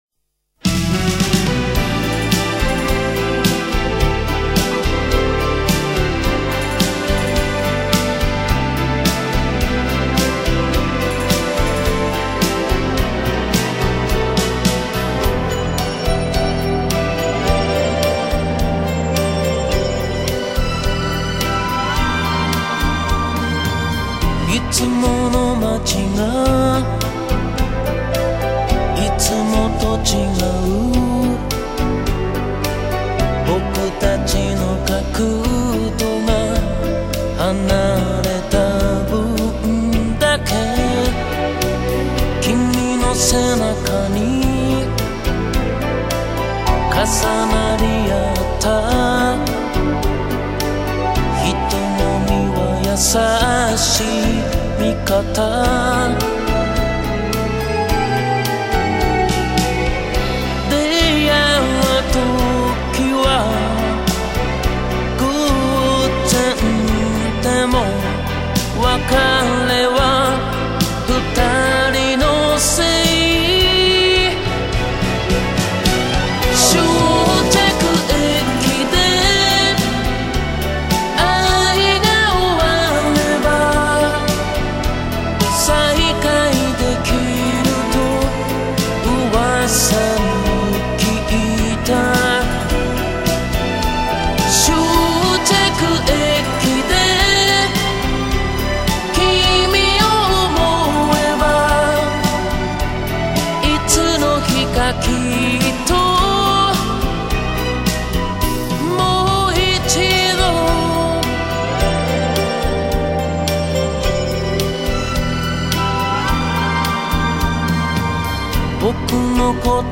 原唱版
高亢清亮